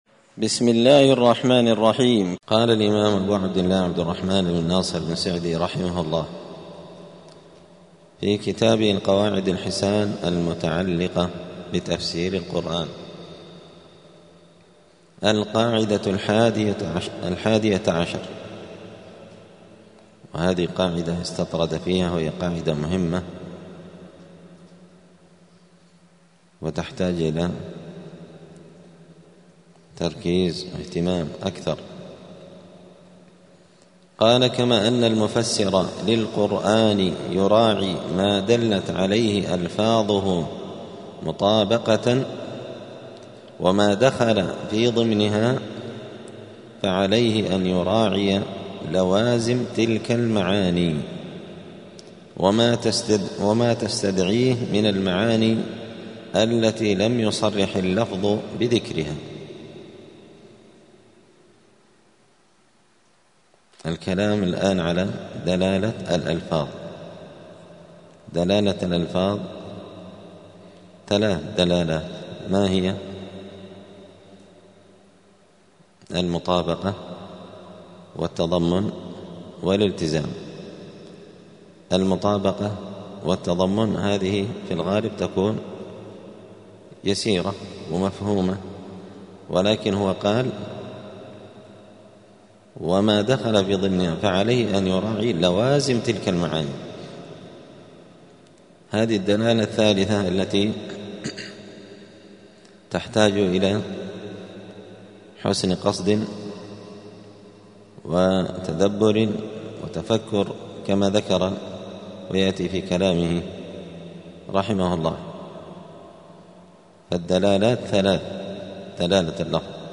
دار الحديث السلفية بمسجد الفرقان قشن المهرة اليمن
12الدرس-الثاني-عشر-من-كتاب-القواعد-الحسان.mp3